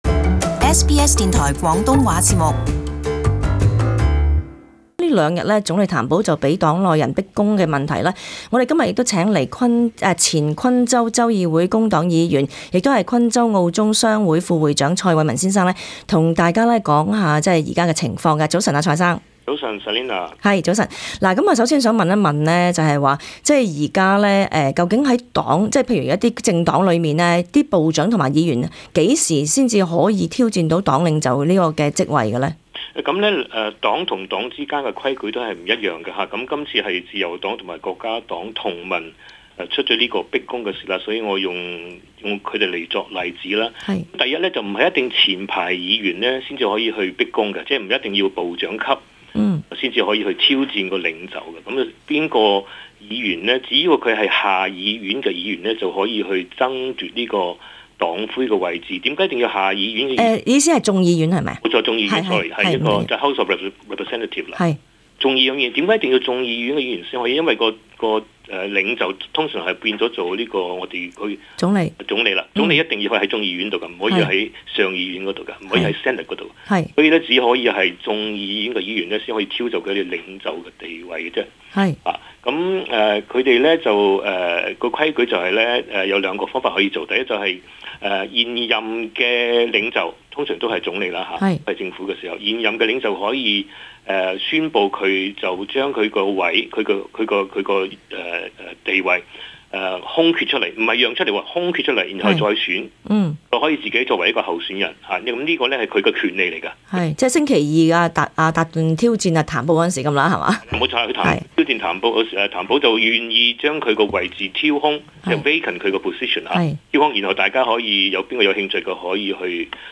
【專訪】前昆州州議會工黨議員談自由黨逼宮